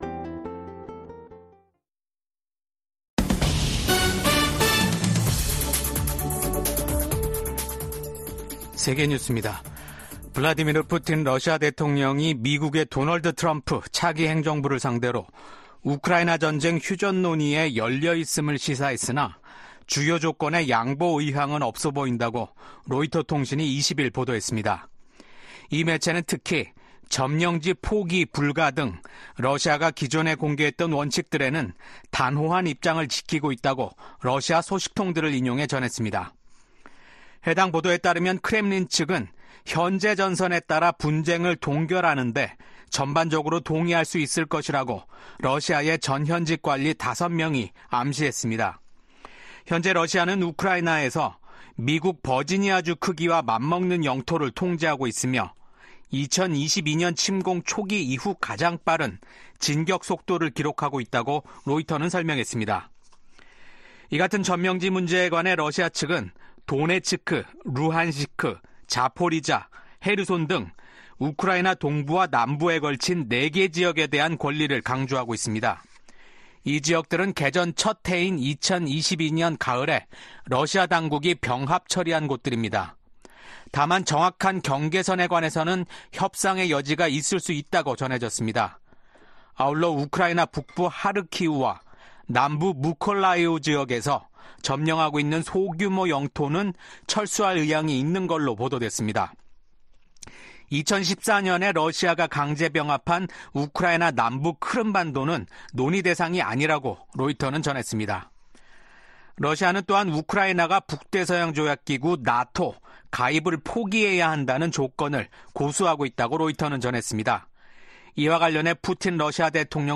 VOA 한국어 아침 뉴스 프로그램 '워싱턴 뉴스 광장'입니다. 한국 정보 당국은 북한 군이 러시아 군에 배속돼 우크라이나 전쟁에 참여하고 있다고 밝혔습니다. 미국 국방부는 러시아의 우크라이나 침략 전쟁에 참전하는 북한군은 정당한 합법적인 공격 목표가 될 것이라고 재차 경고했습니다. 미국의 우크라이나 전문가들은 북한군 파병이 러시아-우크라이나 전쟁에 미치는 영향이 제한적인 것이라고 전망했습니다.